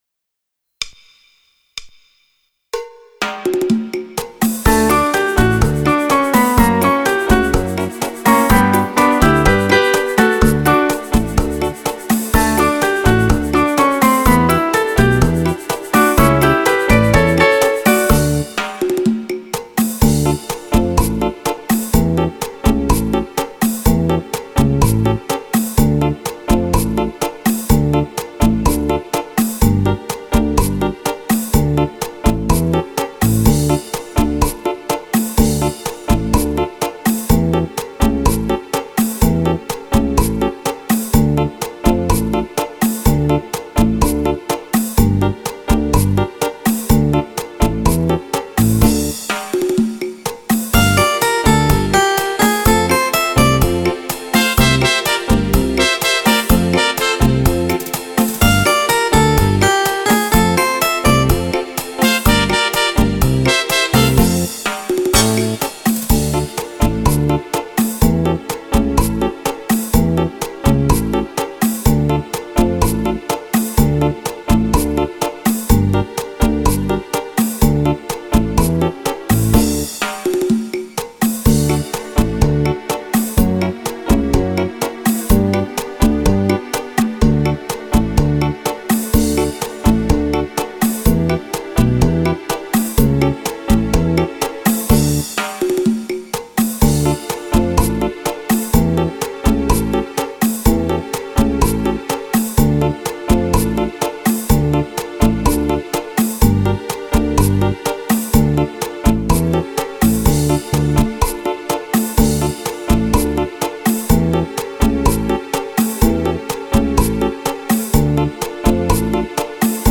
Cha cha cha
Fisarmonica